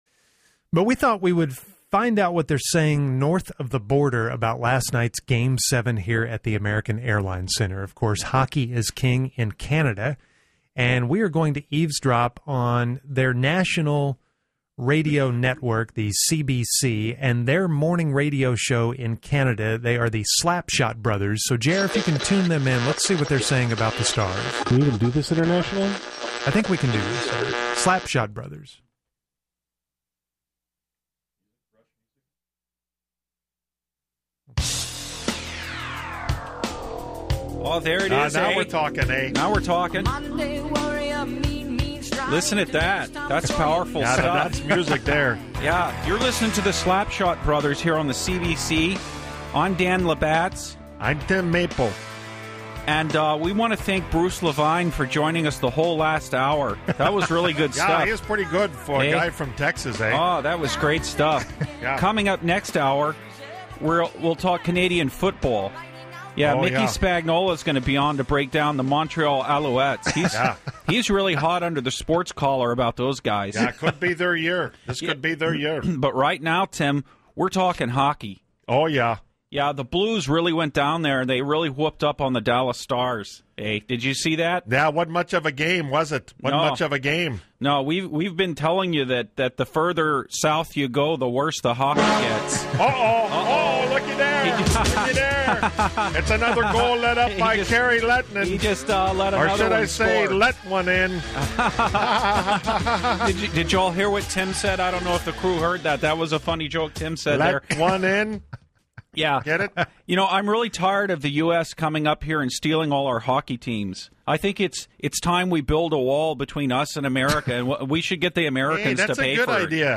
Slapshot Brothers – Canadian Sports Radio – 5.12.16